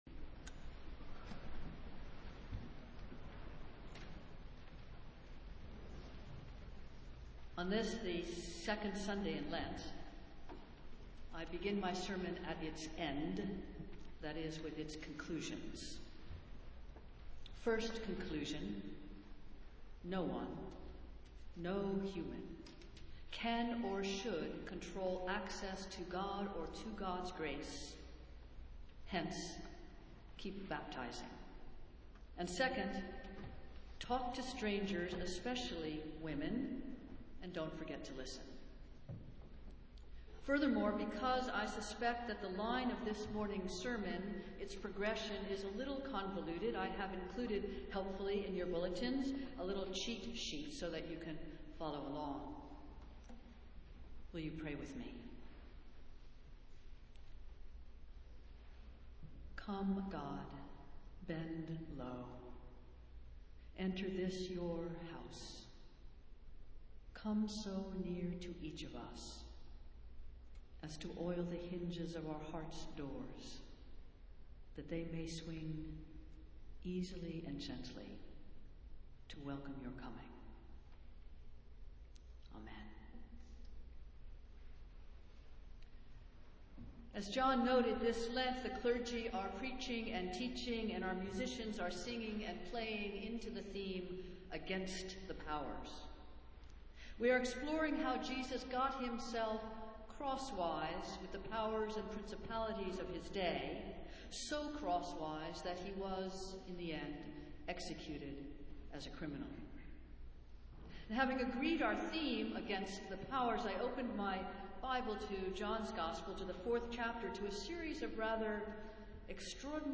Festival Worship - Second Sunday in Lent
On this, the second Sunday in Lent, I begin my sermon, at its end, that is, with its conclusions.